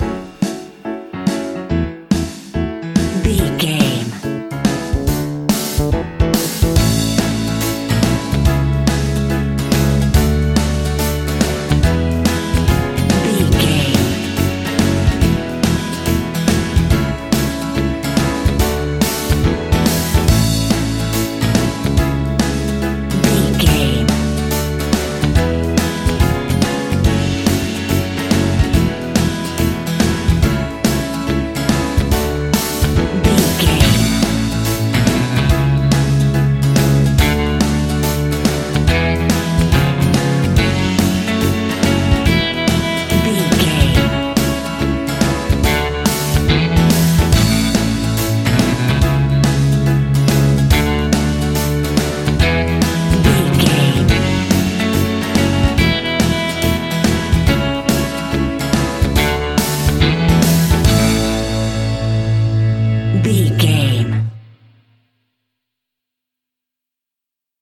Uplifting
Ionian/Major
pop rock
indie pop
fun
energetic
acoustic guitars
drums
bass guitar
electric guitar
piano
organ